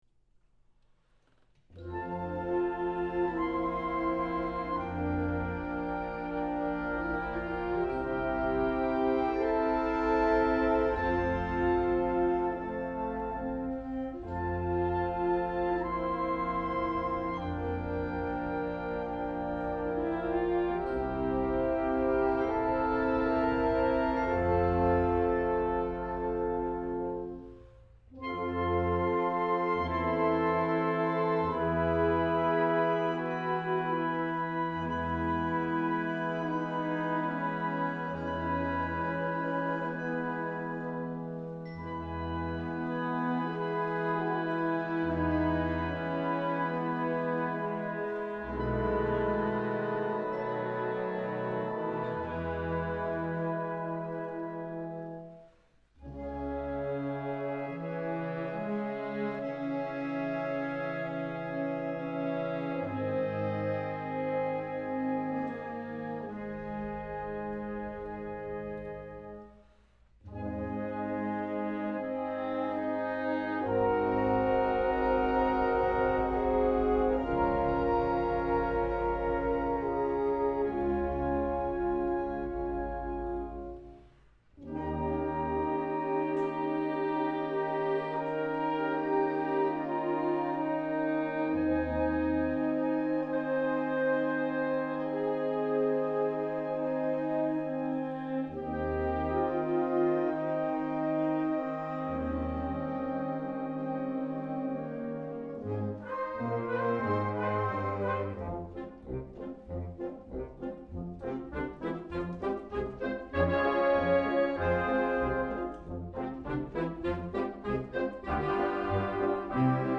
arranged for band
Piccolo
Oboe
Bassoon
Trombone
Euphonium
Tuba
using a pair of Oktava MK-12 omnidirectional microphones